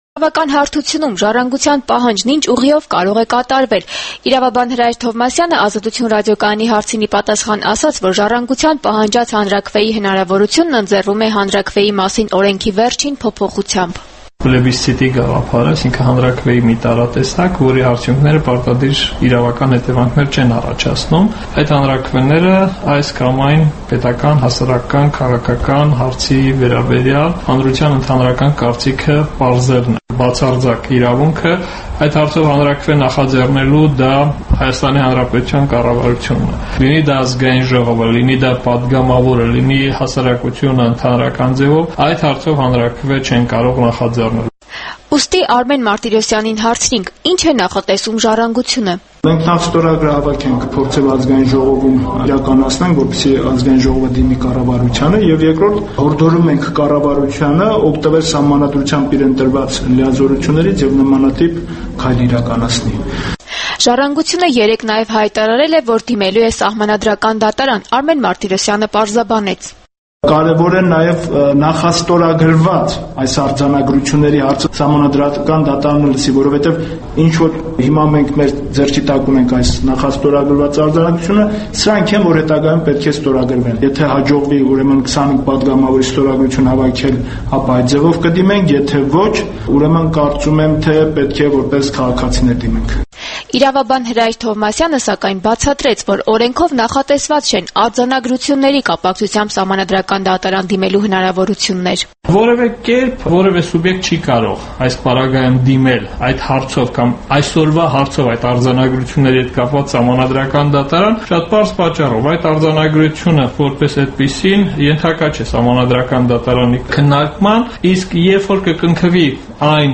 Իրավաբան Հրայր Թովմասյանը ուրբաթ օրը «Ազատություն» ռադիոկայանի հետ զրույցում պարզաբանումներ տվեց ընդդիմադիր «Ժառանգություն» կուսակցության՝ նախաստորագրված հայ-թուրքական արձանագրությունների հարցով հանրաքվեի պահանջի առնչությամբ: